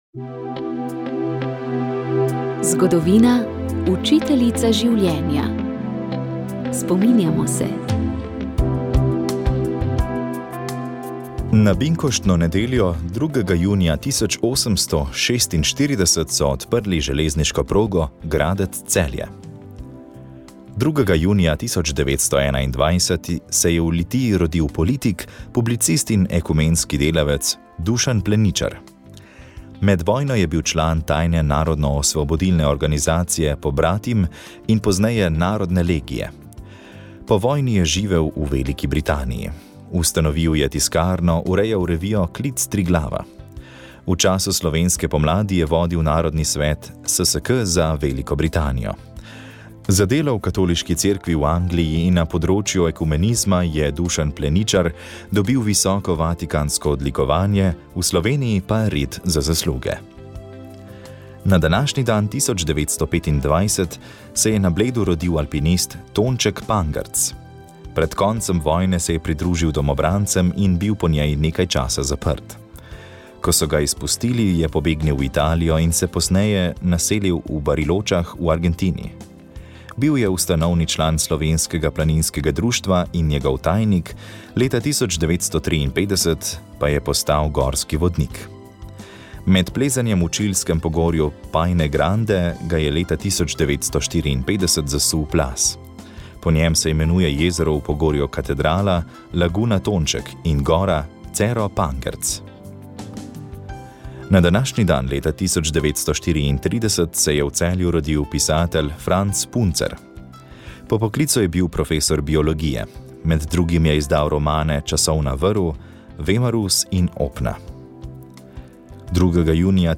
Ob sklepu šmarnične pobožnosti smo v duhovnem večeru slišali Marijine pesmi, s katerimi smo prosili za Marijino varstvo in pomoč. Slišali smo duhovni nagovor, ki ga je za 7. velikonočno nedeljo pripravil nadškof Marjan Turnšek. Sledila je molitev prvih nedeljskih večernic in rožnega venca, s katerim smo molili za vse ustvarjalce medijskih vsebin, saj je jutrišnja nedelja posvečena medijem. Mariji v čast pa smo zapeli tudi lavretanske litanije.